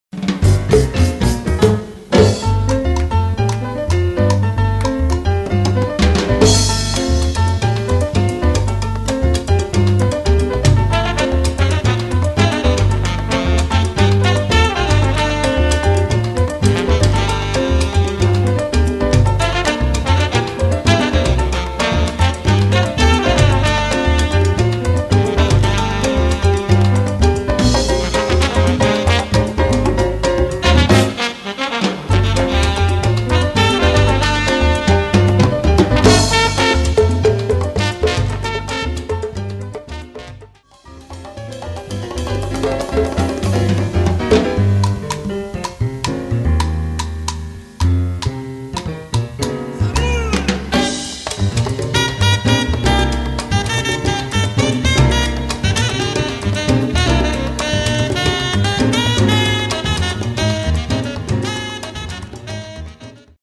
Category: combo (sextet)
Style: mambo
Solos: open
Instrumentation: combo (sextet) trumpet, tenor, rhythm (4)